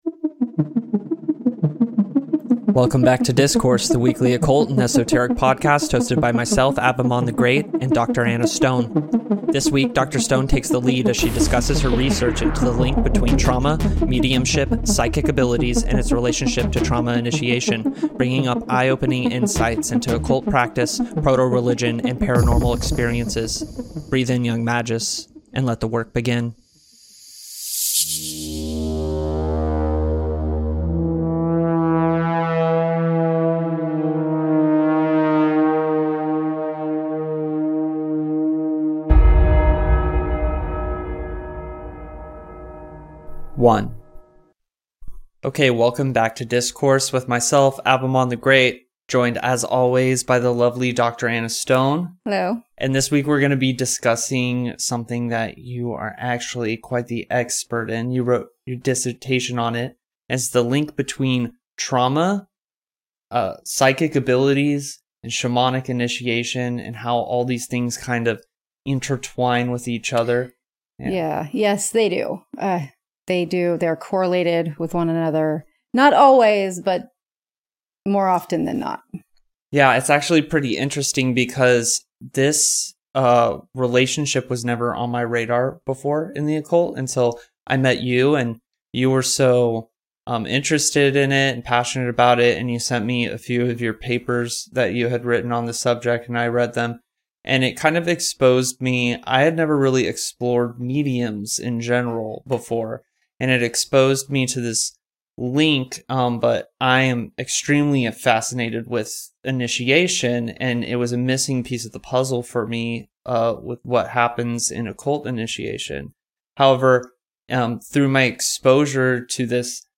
Join us for a powerful conversation at the crossroads of psychology, parapsychology, and the occult.